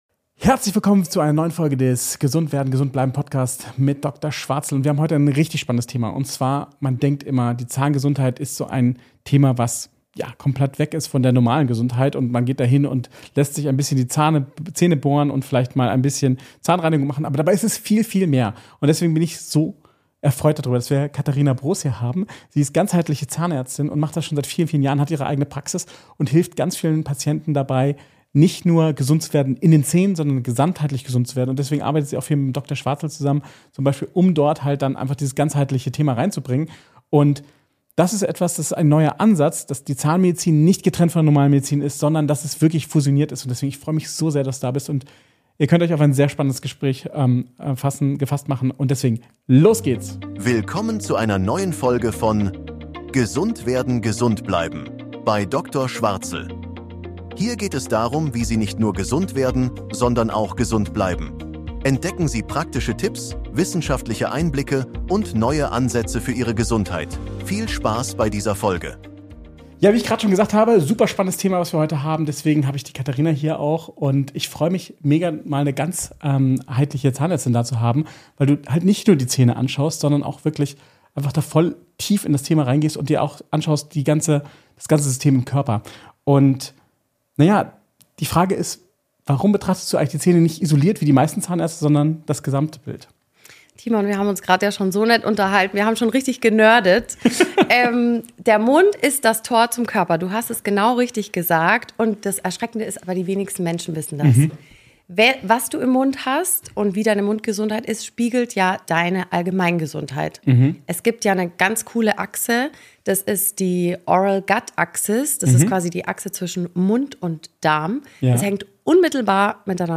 Was deine Zähne über deine Gesundheit verraten | Interview